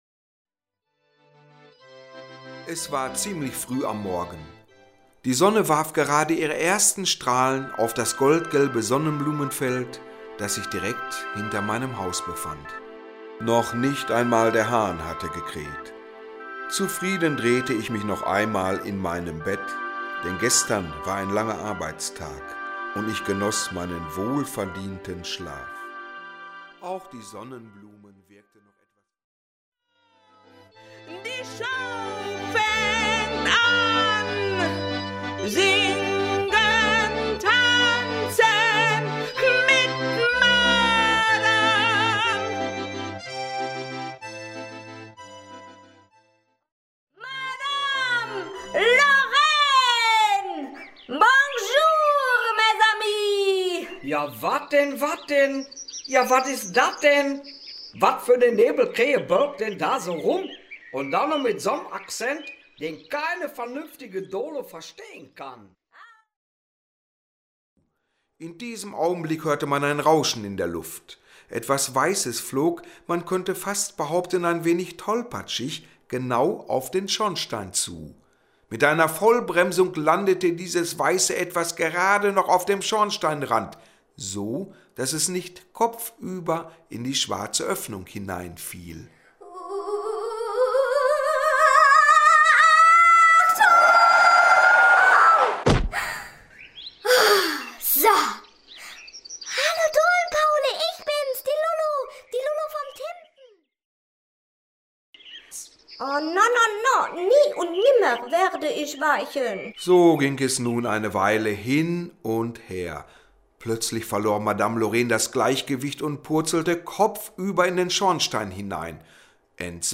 Ein Hörbuch mit Musik